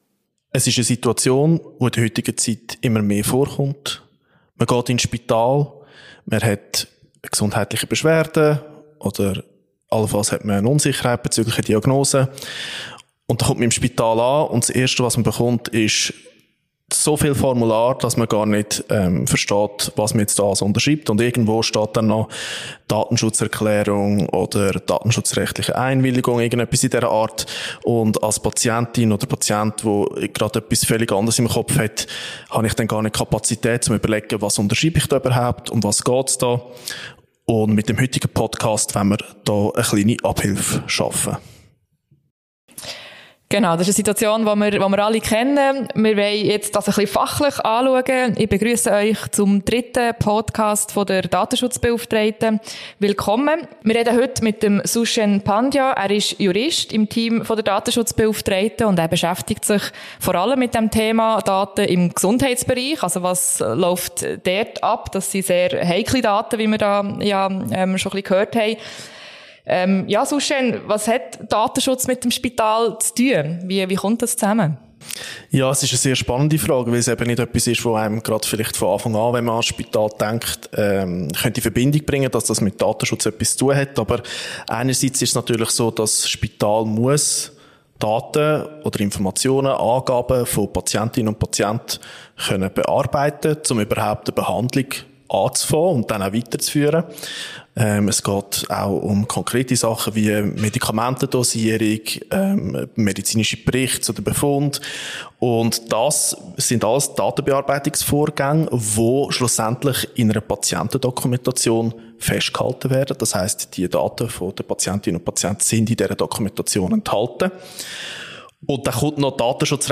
Beschreibung vor 2 Wochen In der dritten Folge datenschutzrelevant – der Zürcher Datenschutz-Talk diskutiert die Datenschutzbeauftragte des Kantons Zürich mit einem Juristen und Teamleiter aus ihrem Team